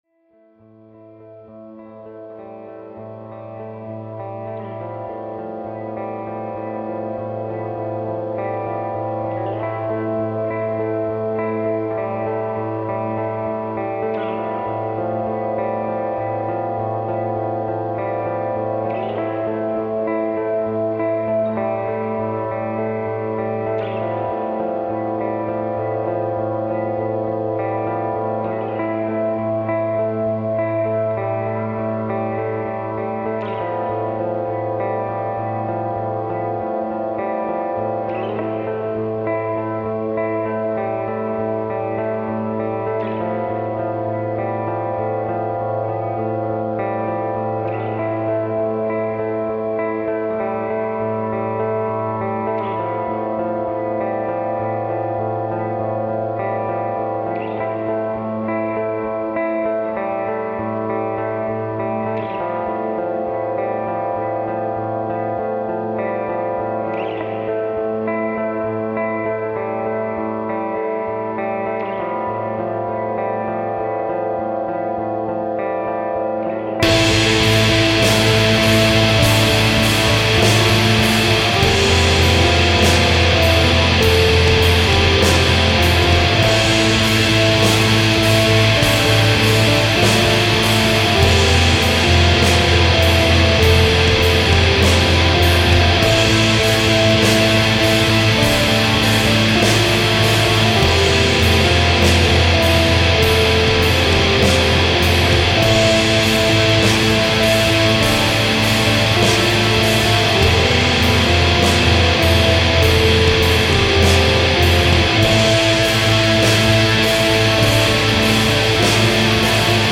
*фоновая композиция –
композиция дааа.. она меня и вдохновила, вероятно. магическая, гипнотическая, воодушевляющая, впитывающаяся